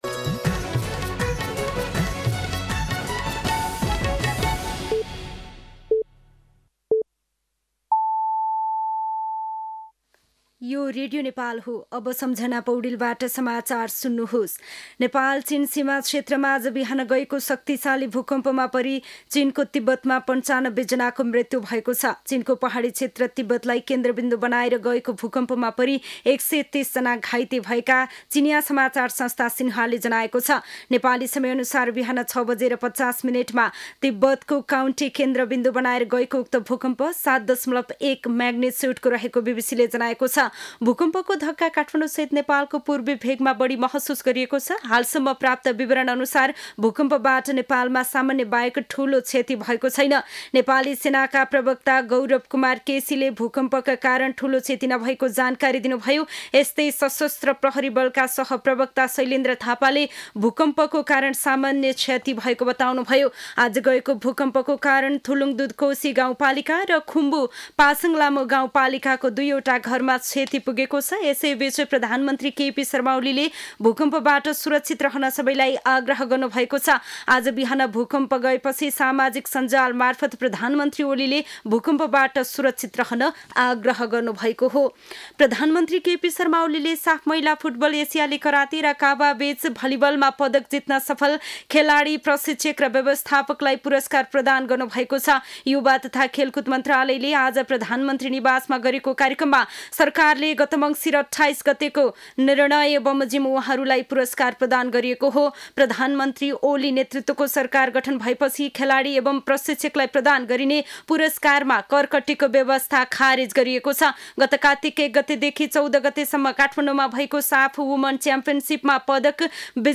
दिउँसो ४ बजेको नेपाली समाचार : २४ पुष , २०८१
4-pm-news-1-1.mp3